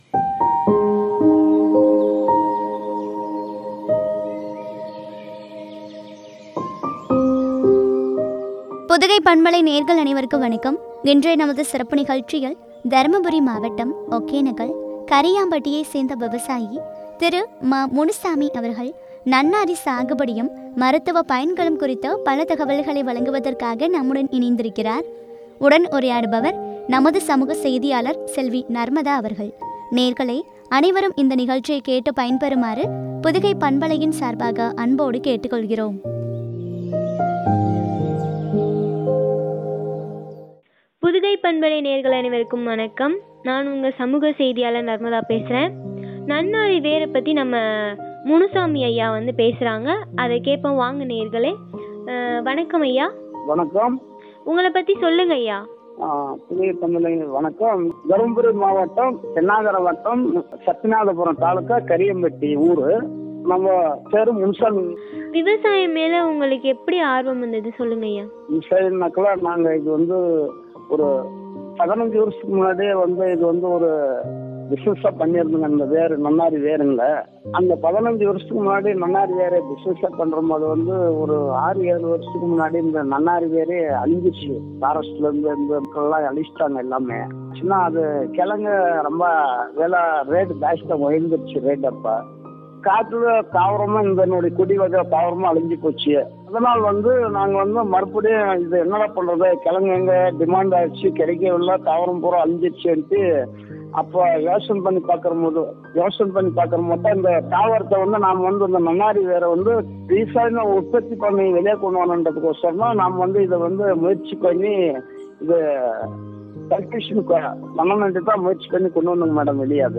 நன்னாரி சாகுபடியும், மருத்துவ பயன்களும் பற்றிய உரையாடல்.